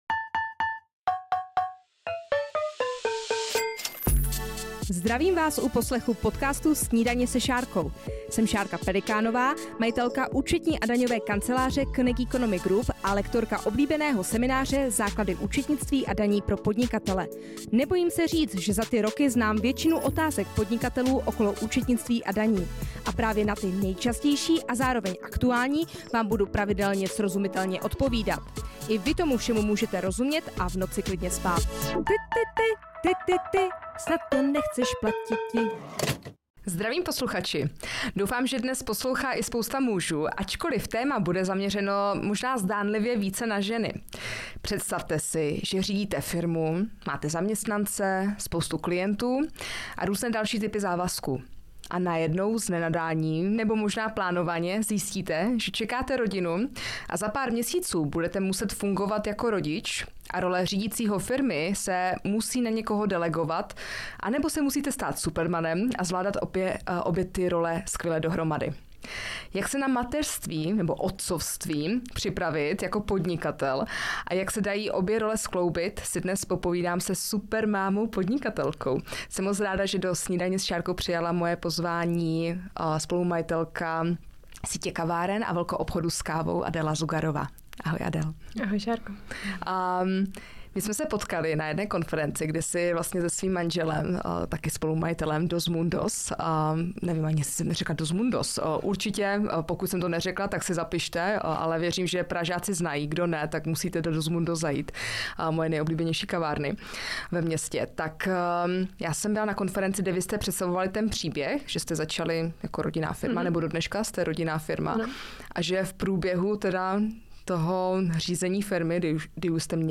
Jak sladit rodinu a podnikání? Upřímný rozhovor s majitelkou kávového byznysu